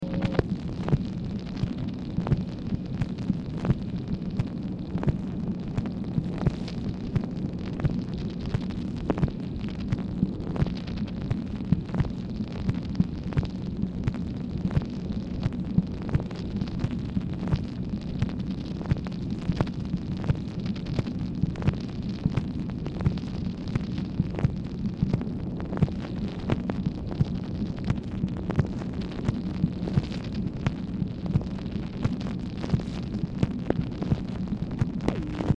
Telephone conversation # 8323, sound recording, MACHINE NOISE, 7/9/1965, time unknown | Discover LBJ
Dictation belt